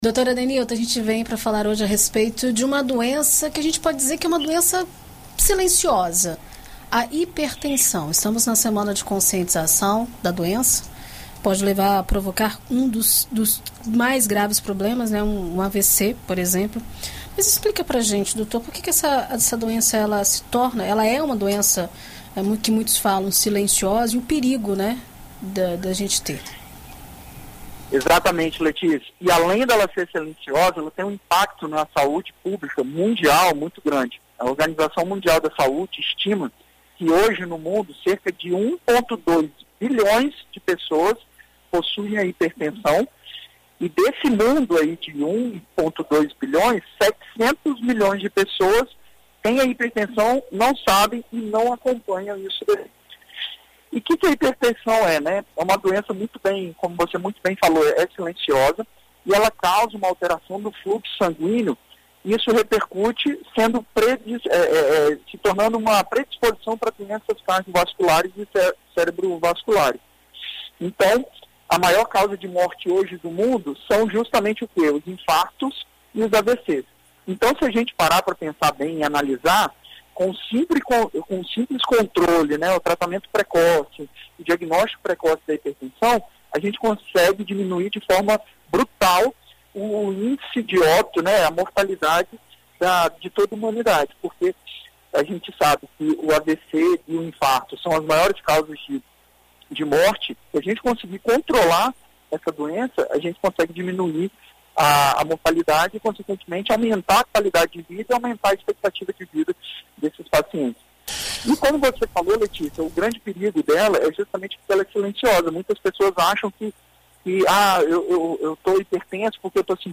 Na coluna Visita Médica desta quinta-feira (28), na BandNews FM Espírito Santo,